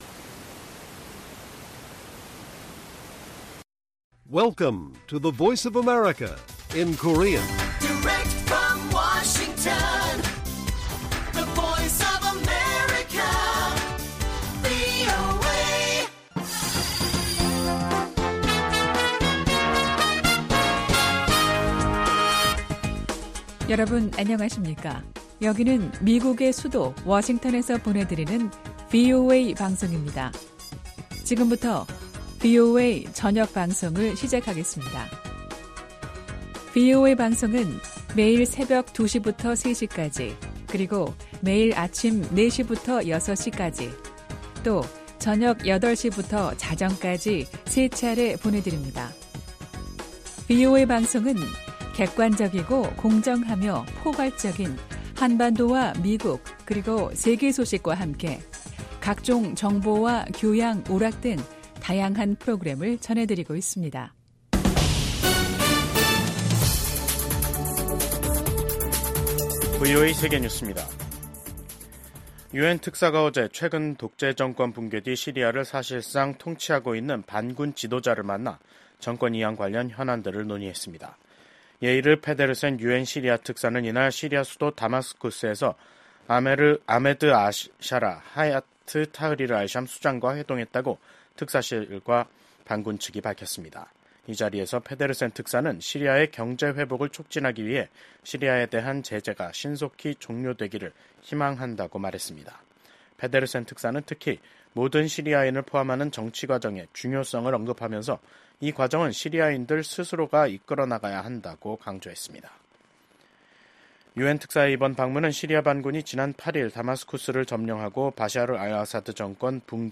VOA 한국어 간판 뉴스 프로그램 '뉴스 투데이', 2024년 12월 16일 1부 방송입니다. 윤석열 한국 대통령이 탄핵소추안 가결로 직무가 정지되면서 윤석열 정부가 추진해 온 강경기조의 대북정책 동력도 약화될 것이라는 전망이 나옵니다. 미국 정부는 윤석열 대통령 탄핵소추안이 한국 국회에서 가결된 것과 관련해 한국의 민주주의와 법치주의에 대한 지지 입장을 재확인했습니다.